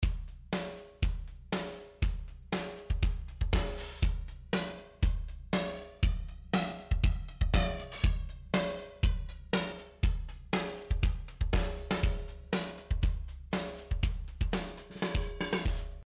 Hier ein Beat mit Toontracks EZdrummer Modern (gehört zum Standardausstattung von EZdrummer 2) und einem Groove aus dem Funk-Erweiterungspack, das Ganze bei 120 BPM:
Für ein erstes Kennenlernen habe ich die Default-Einstellungen von Antresol verwendet, den Preamp bis zu leichten Anzerrungen aufgedreht und in diesem zentralen Modul die LFO to Clock Voltage Curve auf 16 Samples eingestellt. True BBD eignet sich dafür, eine Lo-Fi-Charakteristik einzubringen.
Die Feedback-Regler habe ich automatisiert.
Kleine Puffergrößen führen zu einem harschen, Lo-Fi-mässigen Klang, wie er das erste Audiodemo kennzeichnet.